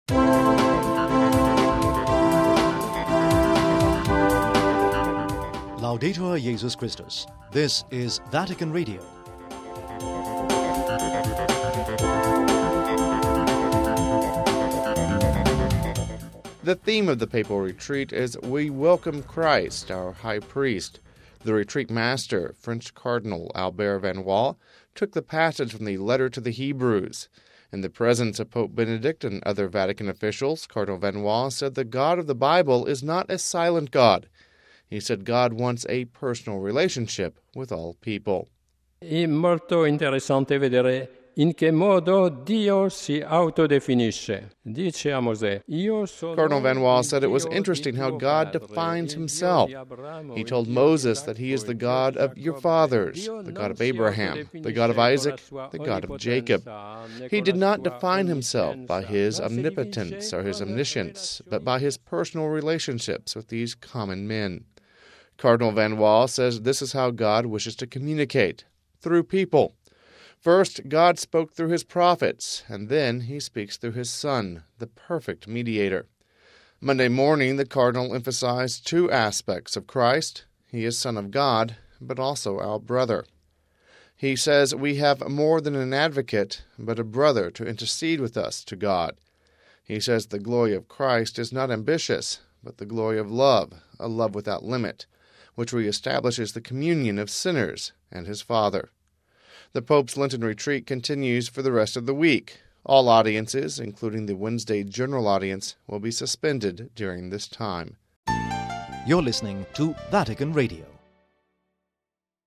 He said God wants a personal relationship with all persons. We have this report...